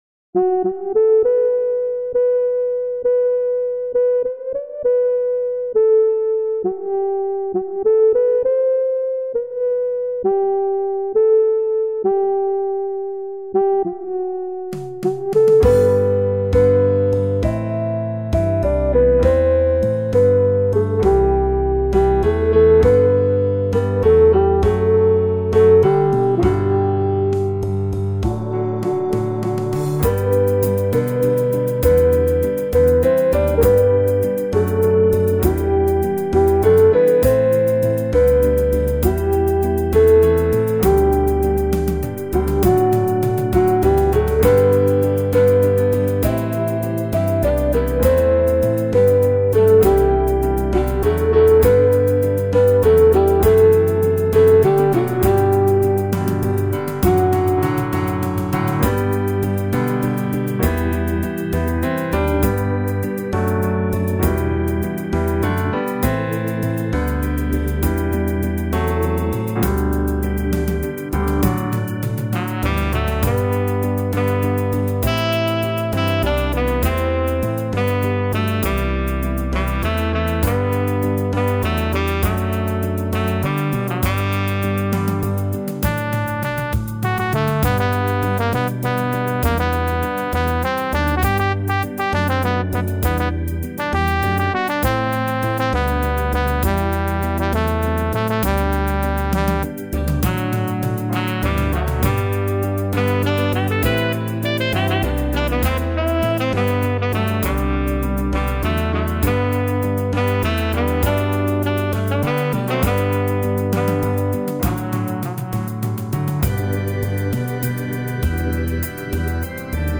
Afro-American Anthem